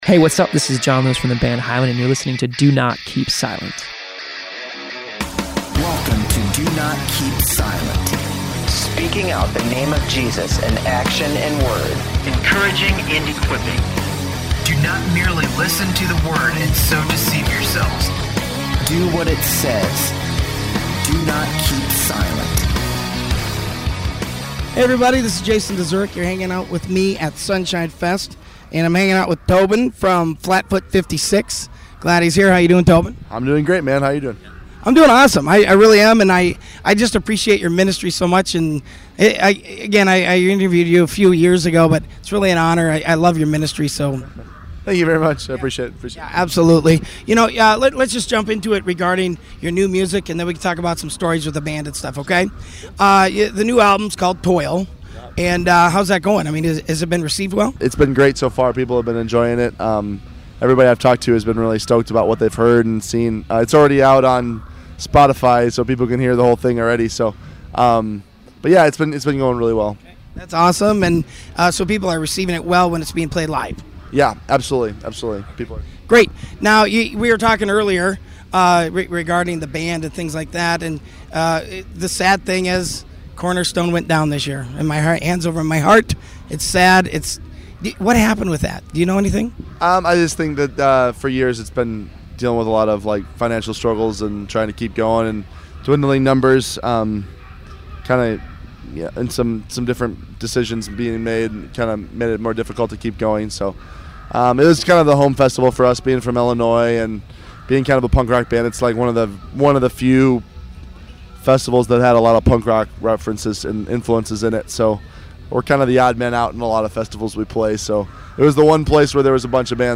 at the Sonshine Festival